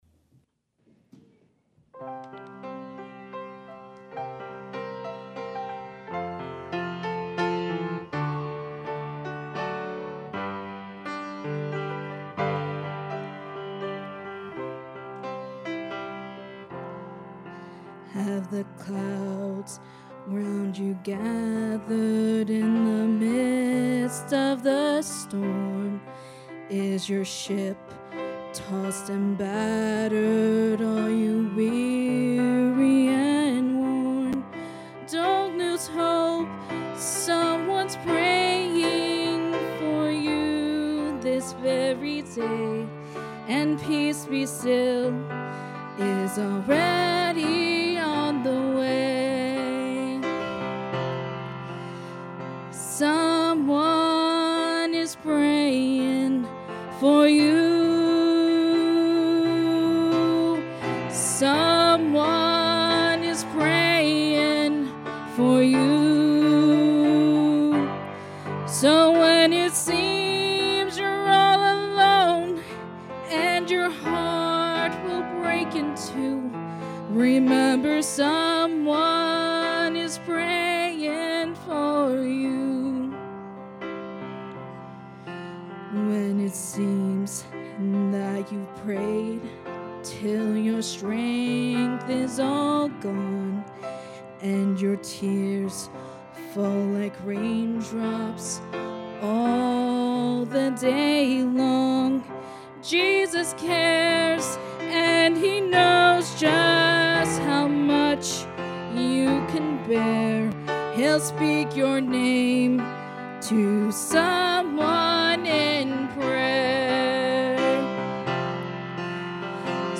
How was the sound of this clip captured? Importance of Being a Friendly Church | Sunday PM